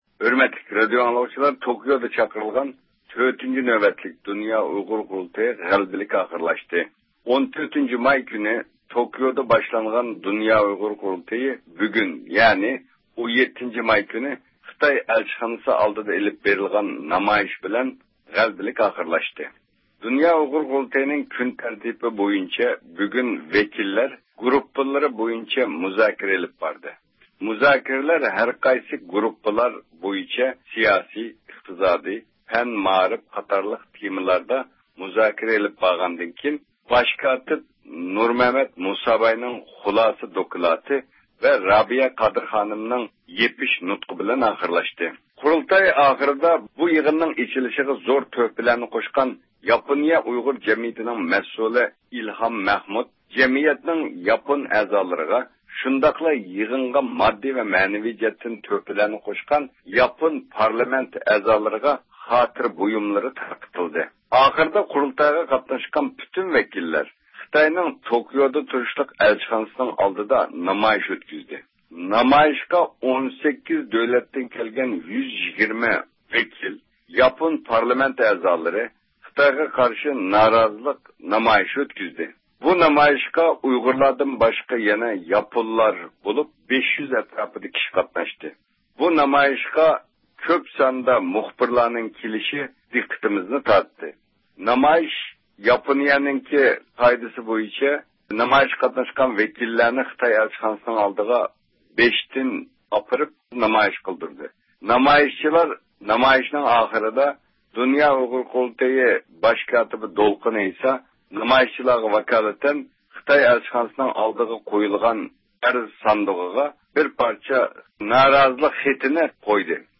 بىز نەق مەيداندىن بۇ پائالىيەت ھەققىدە مەلۇمات ئېلىش ئۈچۈن مىكروفونىمىزنى ئۇلارغا ئۇزاتتۇق.